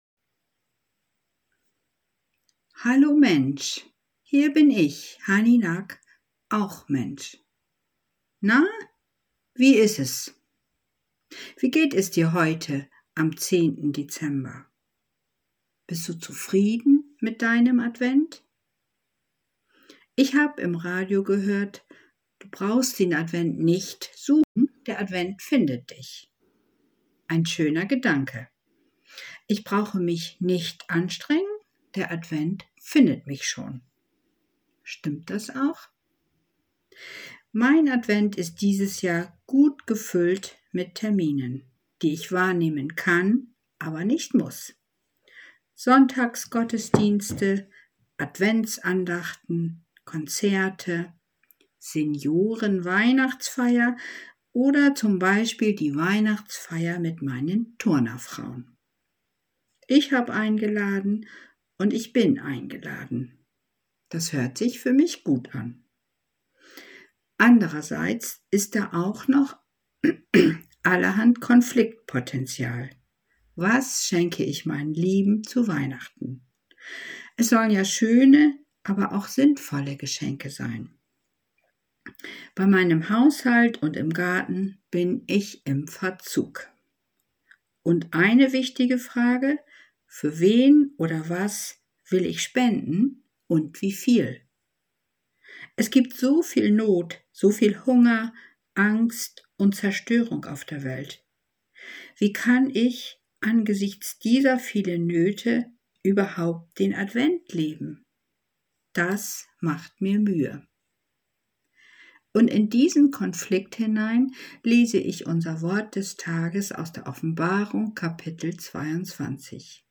Audio-Dateien der Andachten (Herrnhuter Losungen)